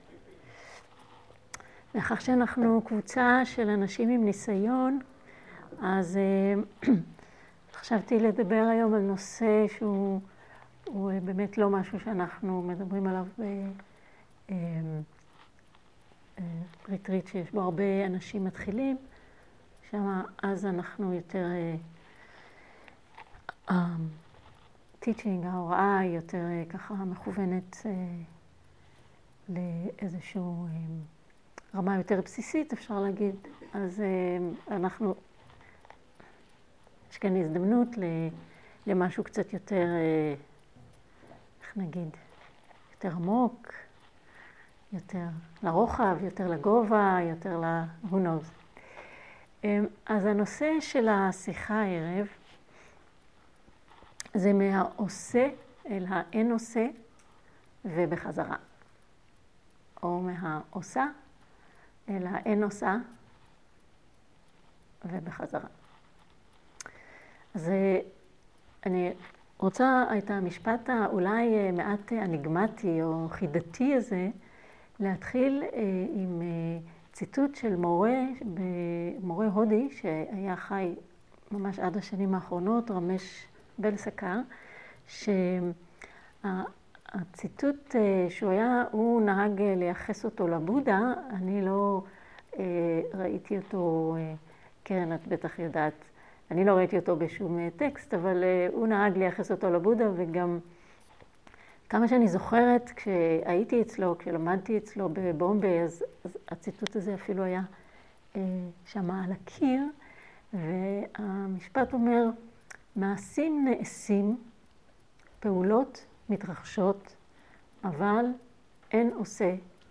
Dharma type: Dharma Talks
Dharma talk Quality: high quality